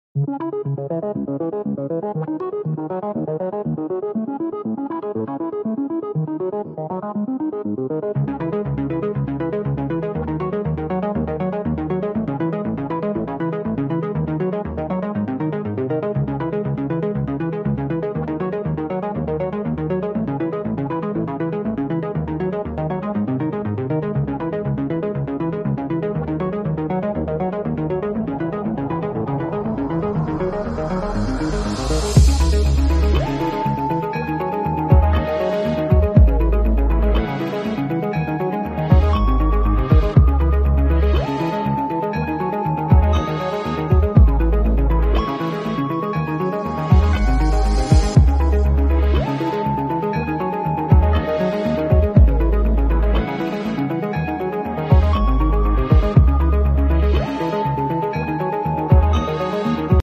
Just tones, beeps, and pure machine-to-machine chatter we can’t decode.